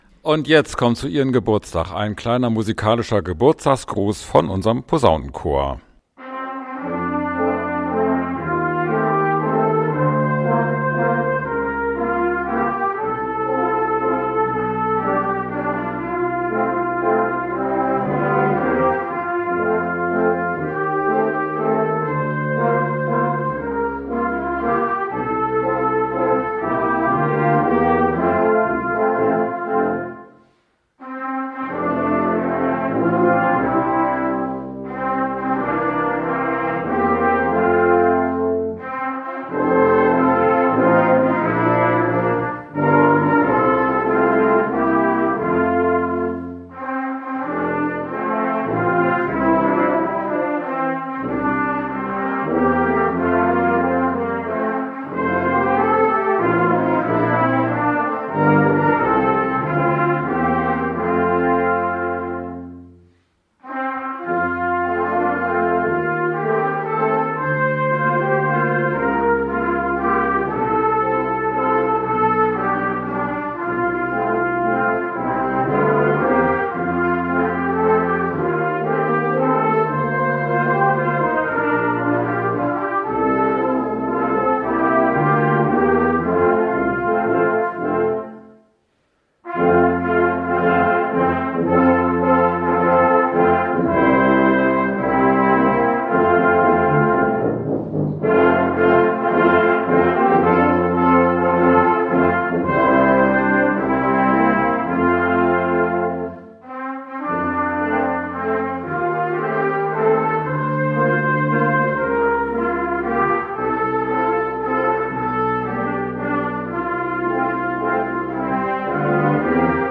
...und hier ist der Geburtstagsgruss des Posaunenchors der